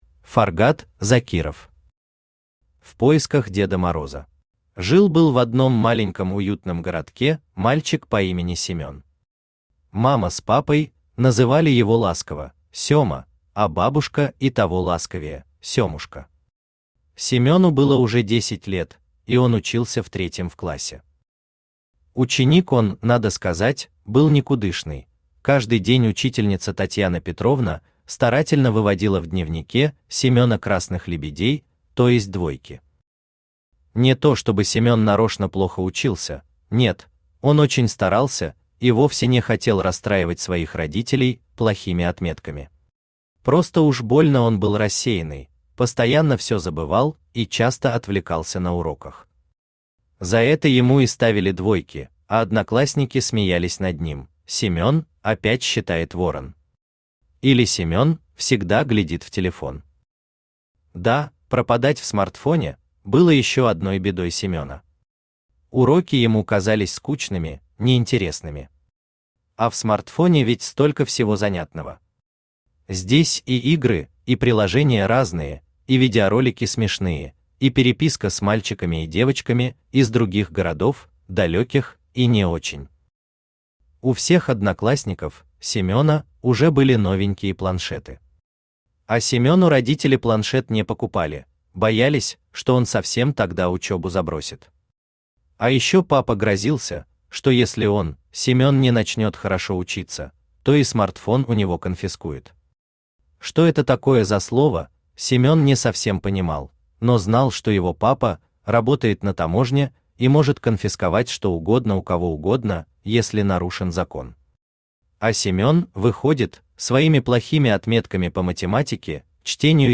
Аудиокнига В поисках Деда Мороза | Библиотека аудиокниг
Aудиокнига В поисках Деда Мороза Автор Фаргат Закиров Читает аудиокнигу Авточтец ЛитРес.